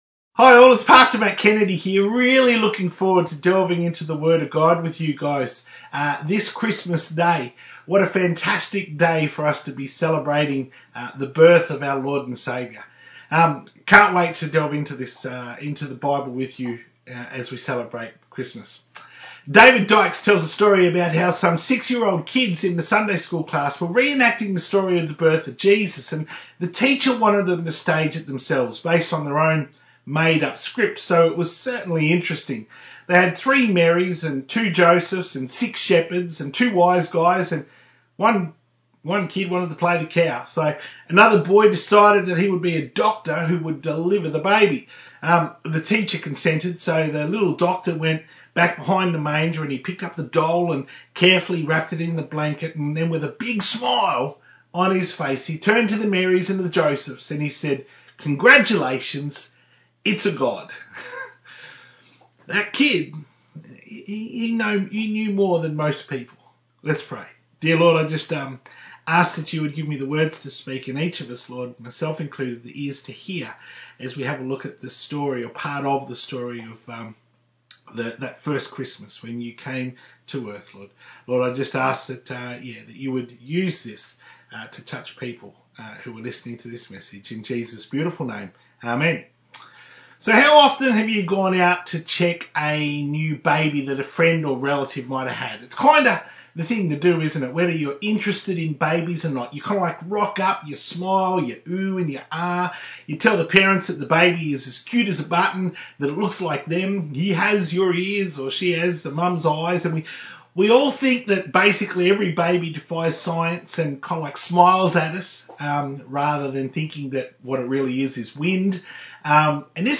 To view the Full Service from 25th December 2020 on YouTube, click here.